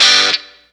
Track 02 - Guitar Stab OS 07.wav